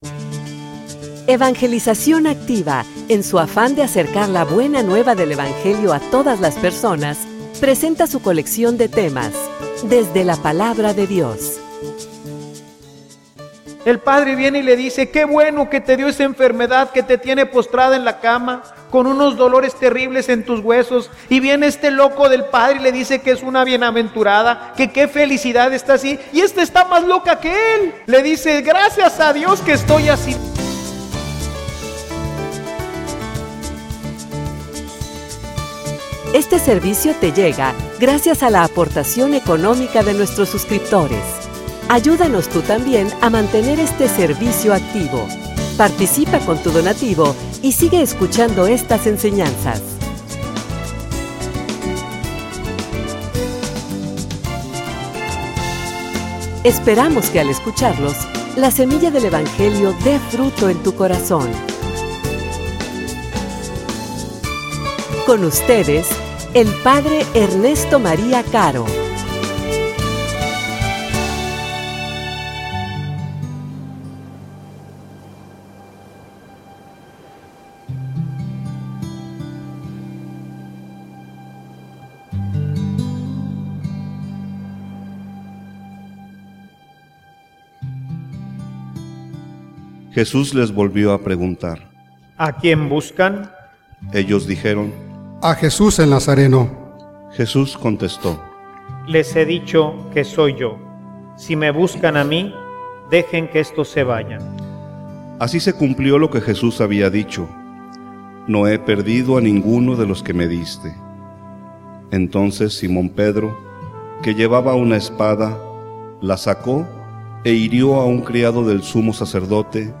homilia_Un_loco_de_amor.mp3